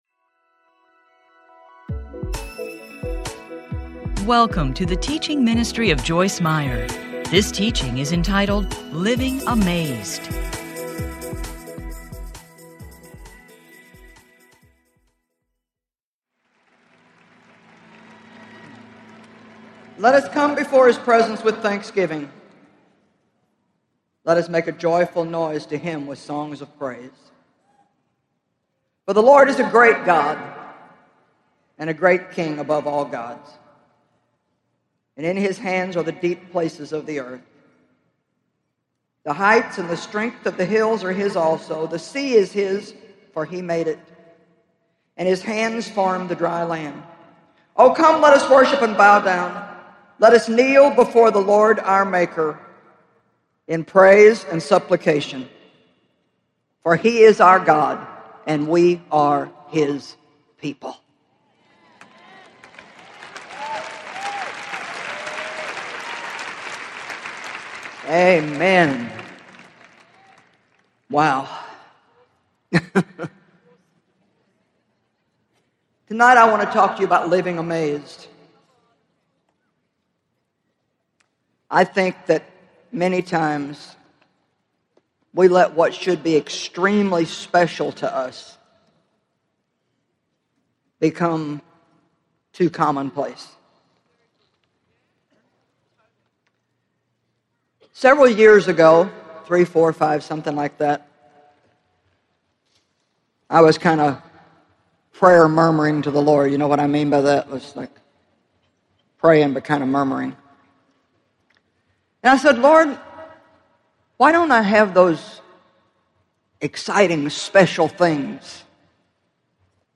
Filled with Hope Teaching Series Audiobook
Narrator
Joyce Meyer
3.25 Hrs. – Unabridged